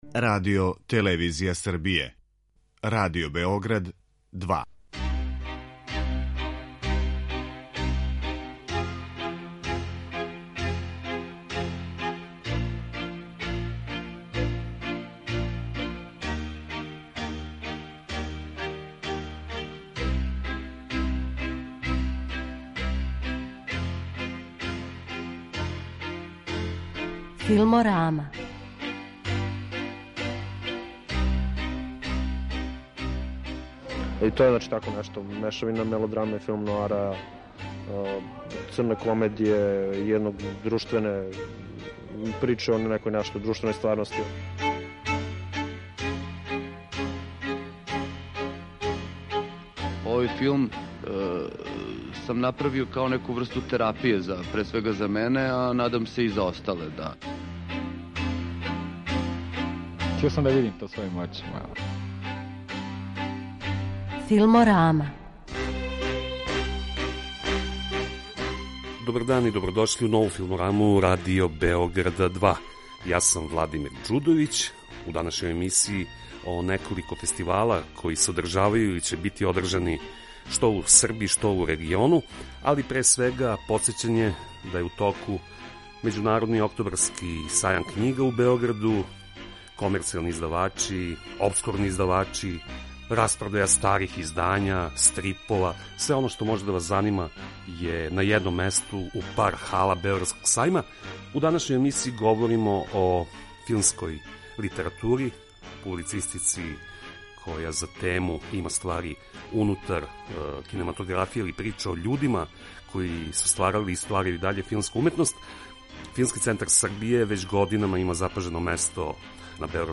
Представљамо и Седми фестивал италијанског филма, који се одржава од 25. до 28. октобра у Београду. Доносимо и репортажу са филмског фестивала у Тузли, а говорићемо и о Међународном фестивалу дечјег и омладинског филма „ИКТ филм фест", који почиње у среду, 26. октобра у Новом Пазару.